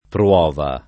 pruova [ pr U0 va ] → prova